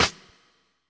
Snare Admiral 2.wav